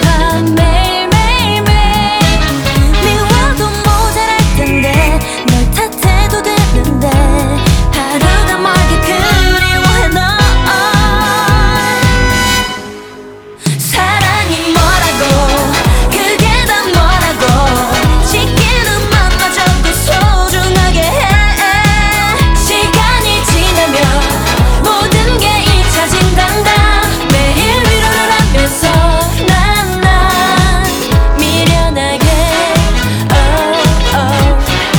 Жанр: Танцевальные / Поп / K-pop